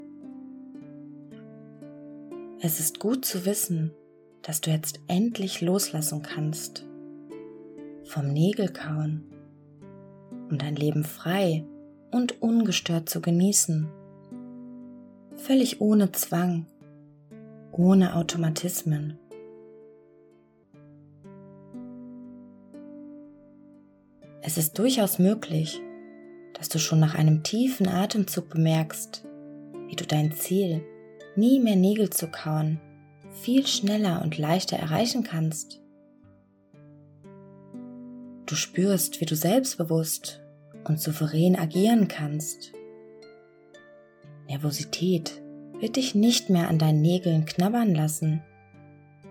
Hypnose gegen Nägelkauen
Diese geführten Hypnose unterstützt Sie dabei, diese nervige Angewohnheit endlich abzulegen. Dazu werden Sie zunächst in einen wunderbaren tiefen Entspannungszustand versetzt.